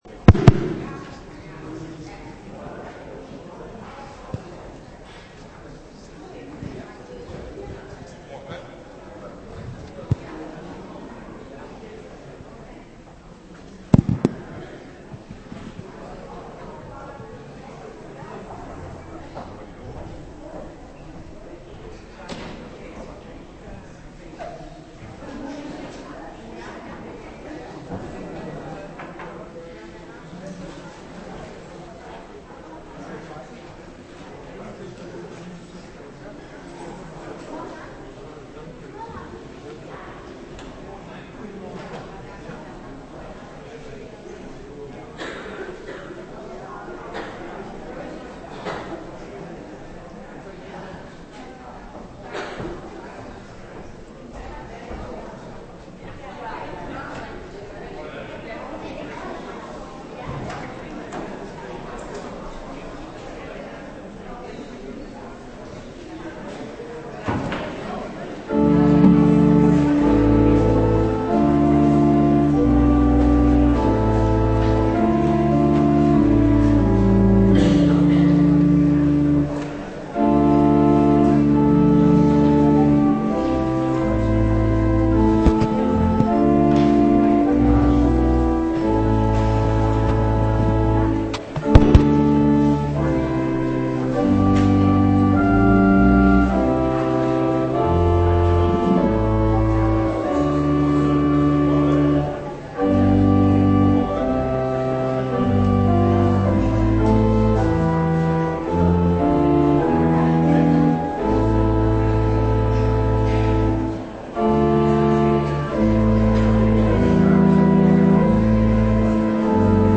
Preek over 1 Korinthe 15:25,26 op zondagmorgen 24 april 2016 - Pauluskerk Gouda